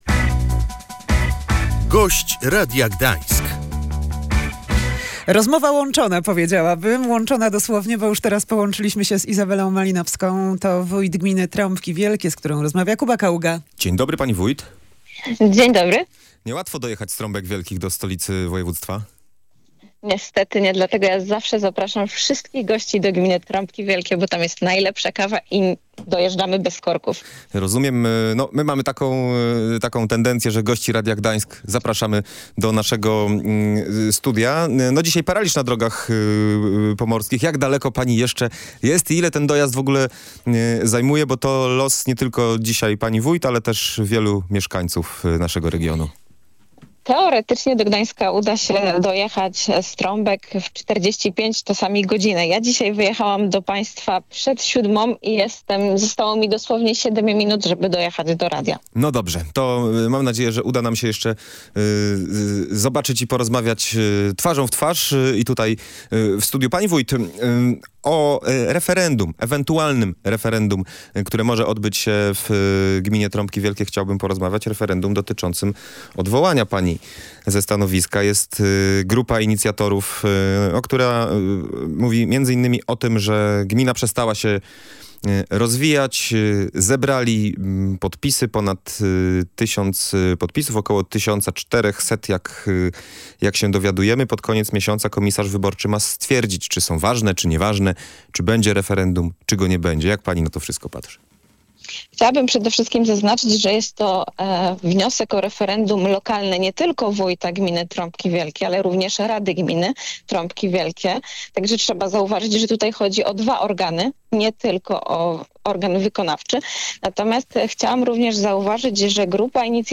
Tymczasem Izabela Malinowska mówiła w Radiu Gdańsk, że chce rozbić funkcjonujące do tej pory układy.